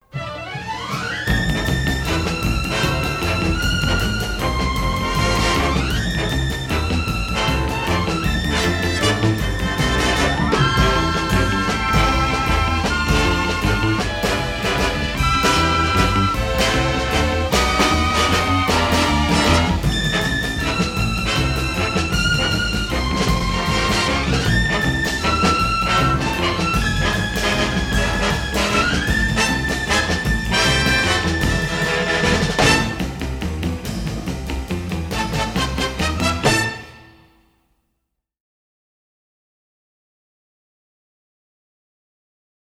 are presented in dynamic stereo